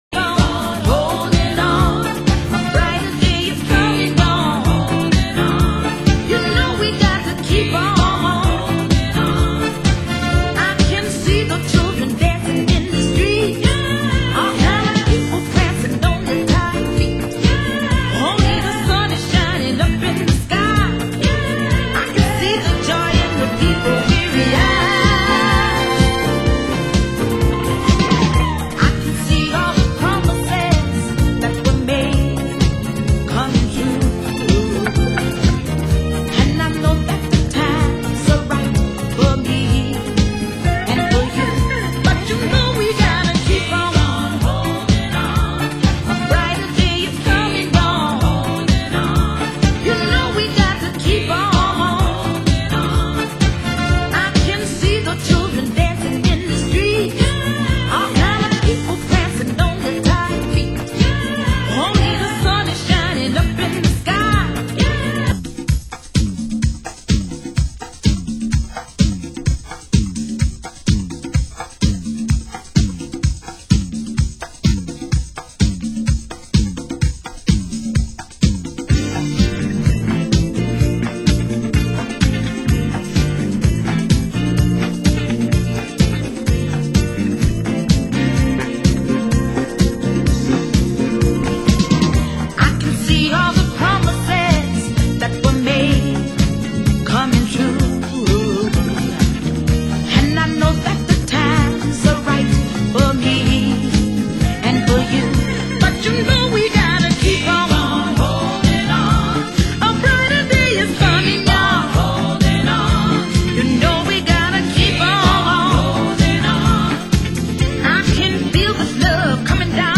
Format: Vinyl 12 Inch
Genre: Disco